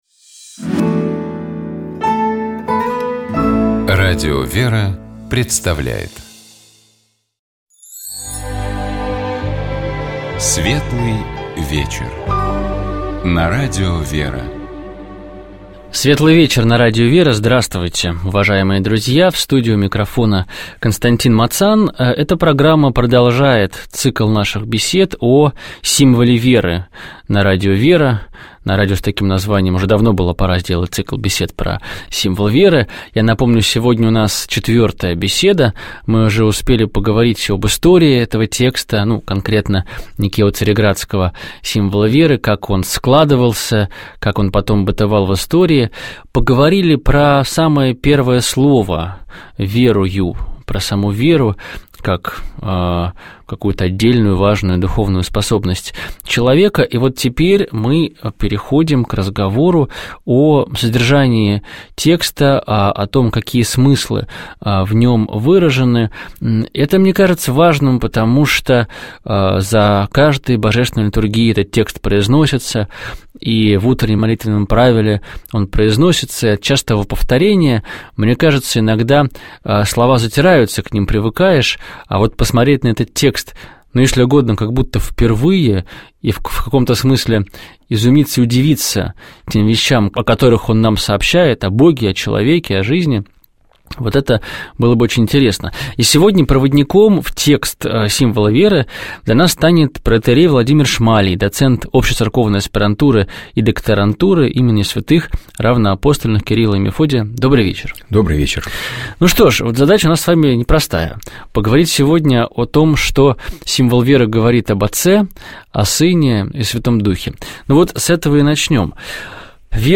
священник